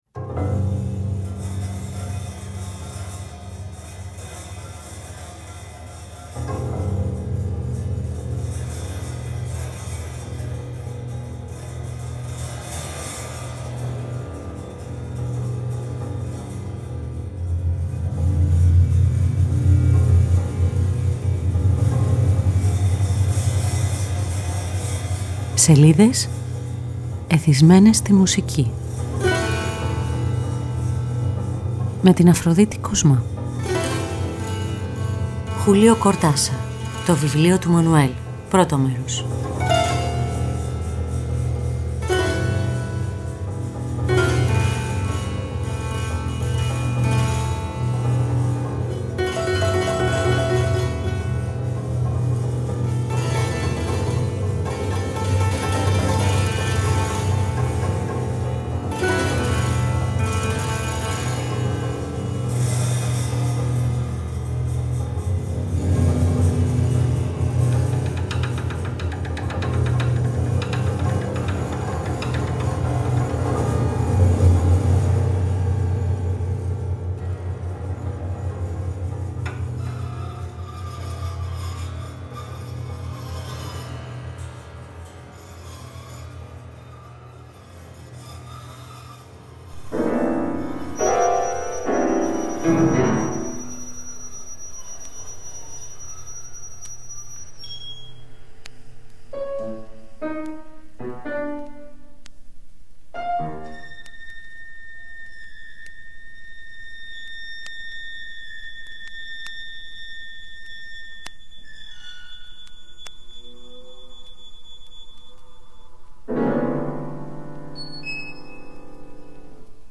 Επιμέλεια, αφήγηση